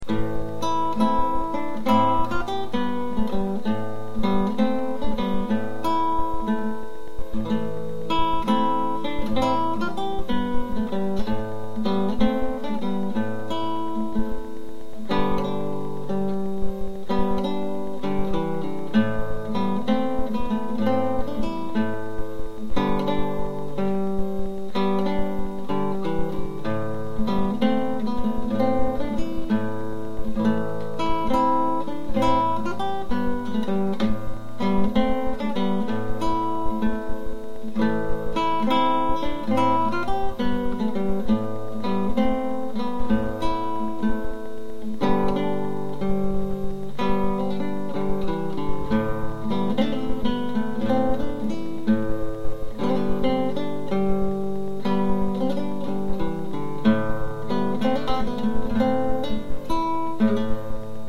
Bobbing Joe - Playford (1651) - Guitare Classique
Une dance traditionnelle (mon arrangement)